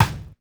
DrRim3.wav